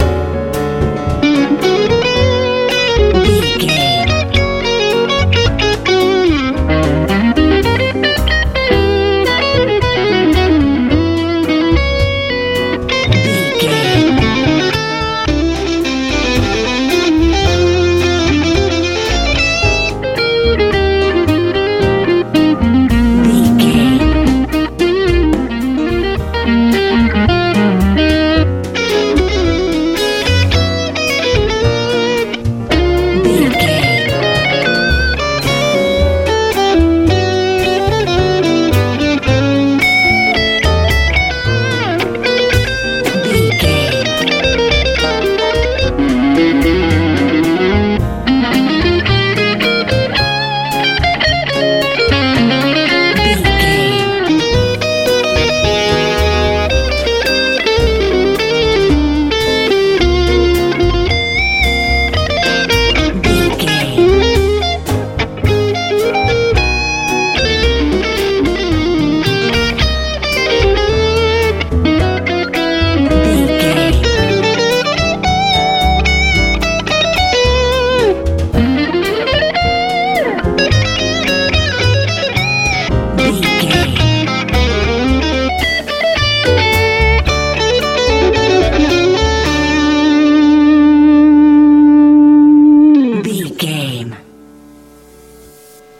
Ionian/Major
C♯
groovy
funky
bass guitar
drums
piano
electric guitar
energetic
driving
smooth